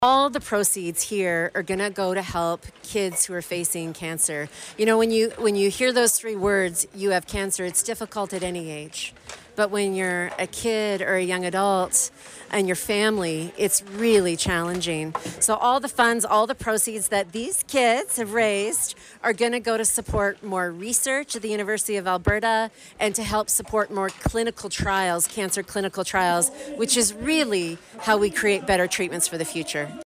She spoke to Windspeaker Media and gave her thoughts on the games and the youth involved.